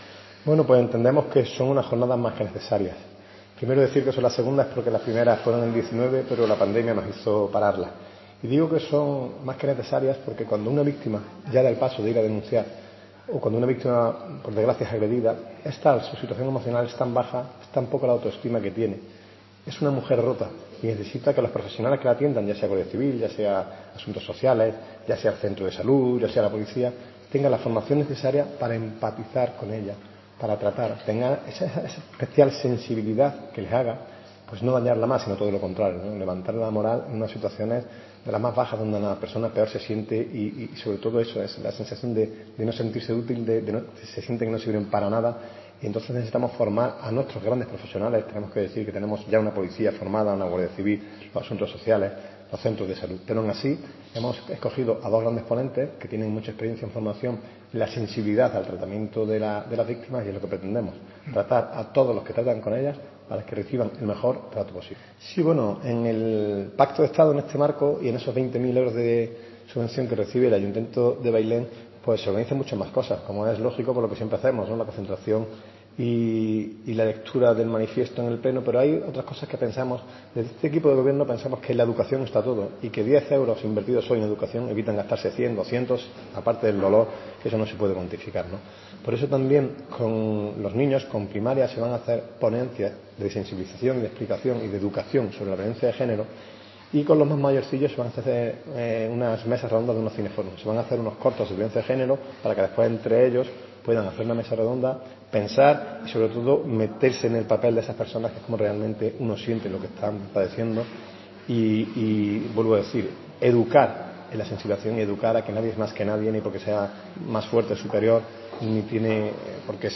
Presentación II Jornadas de formación para profesionales